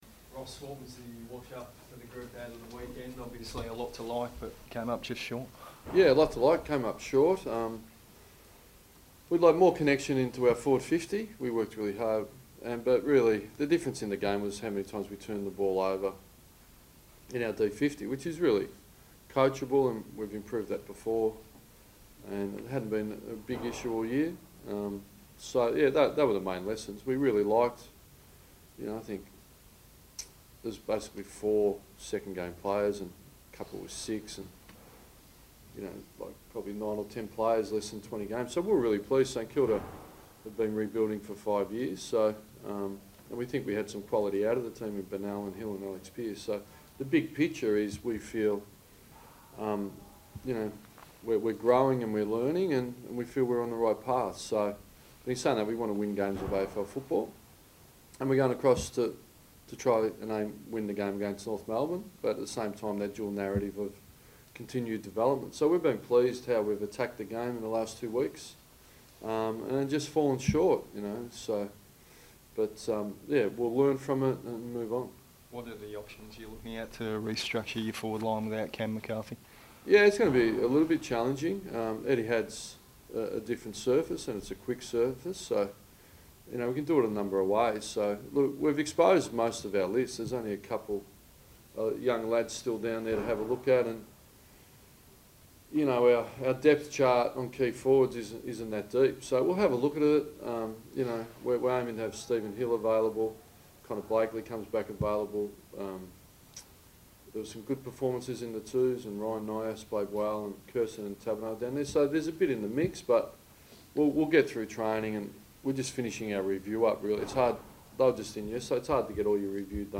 Ross Lyon media conference - Wednesday 5 July 2017